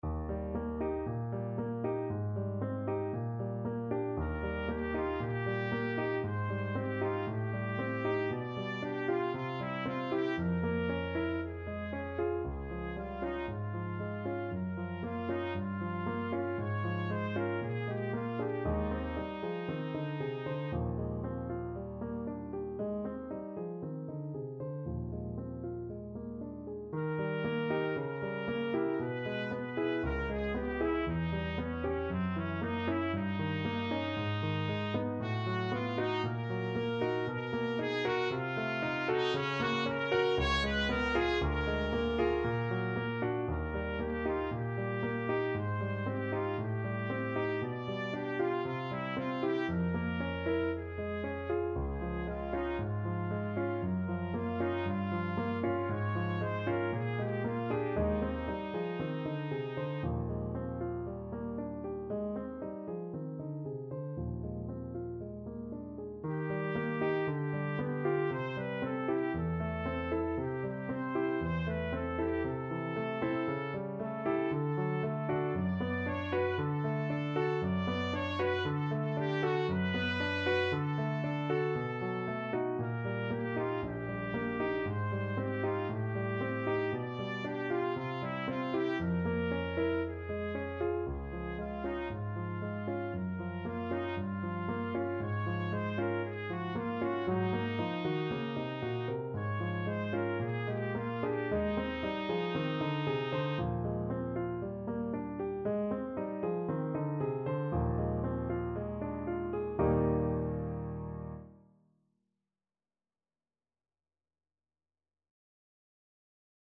Trumpet version
4/4 (View more 4/4 Music)
Allegro moderato =116 (View more music marked Allegro)
Classical (View more Classical Trumpet Music)